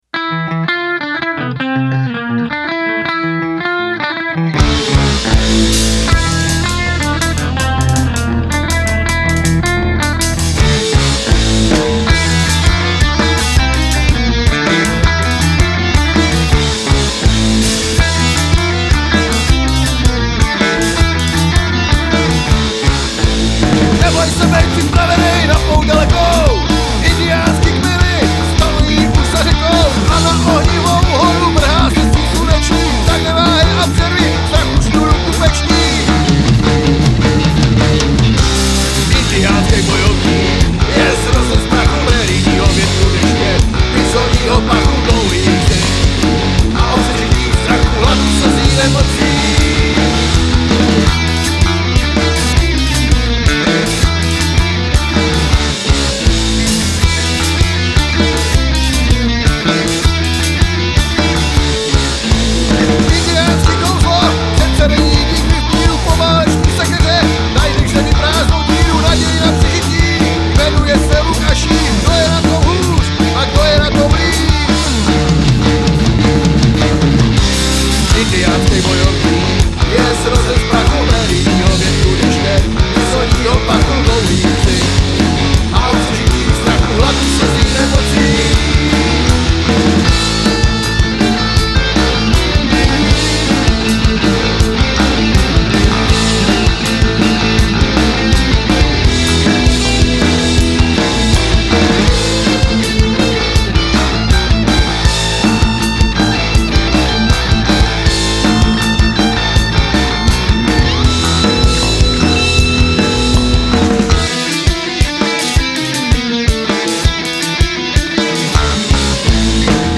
Basa = Schecter + GK (linka + mikrofon)